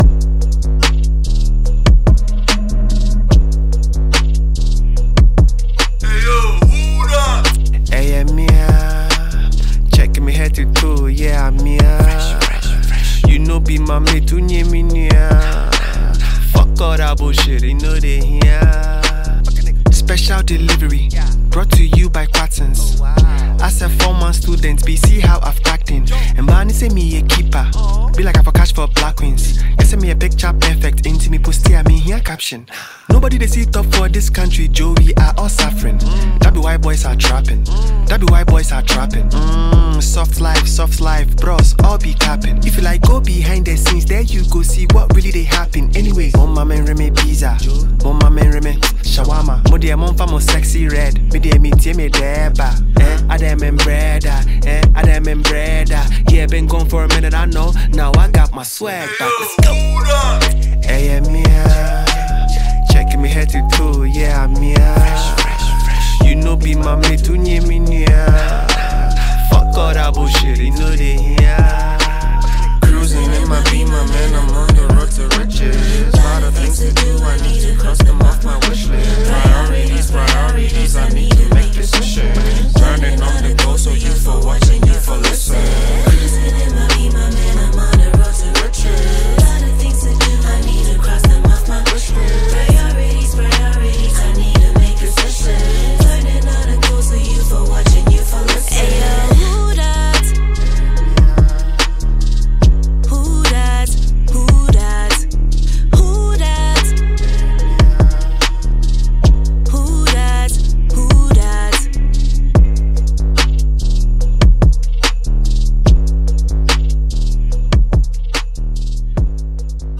creating a perfect blend of Afrobeat and urban vibes.